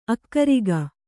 ♪ akkariga